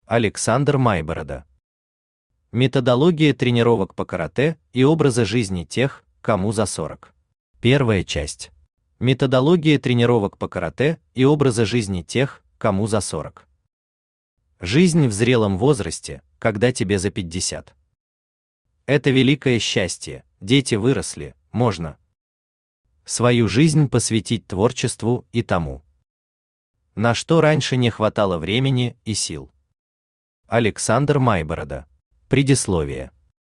Аудиокнига Методология тренировок по Каратэ и образа жизни тех, кому за сорок. 1 часть | Библиотека аудиокниг